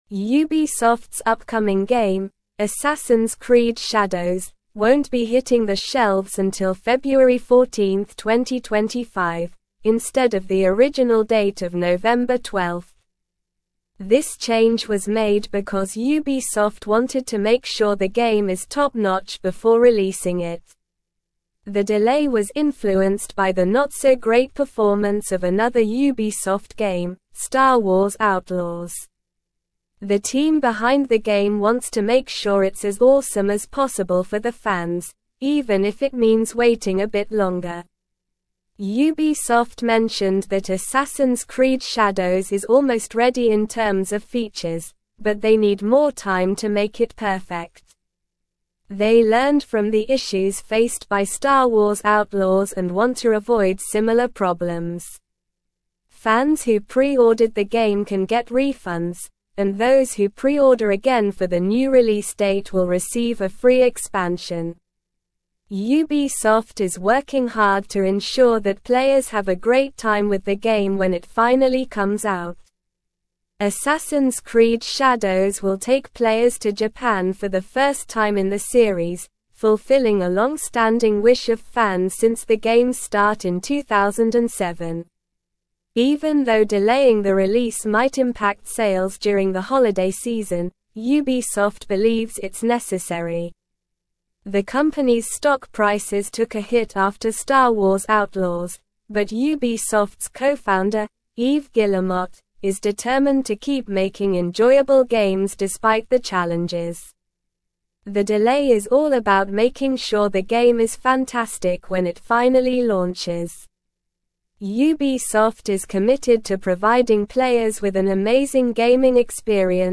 Slow
English-Newsroom-Upper-Intermediate-SLOW-Reading-Ubisoft-Delays-Assassins-Creed-Shadows-Release-to-2025.mp3